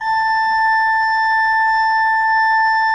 Index of /90_sSampleCDs/Propeller Island - Cathedral Organ/Partition L/ROHRFLUTE MR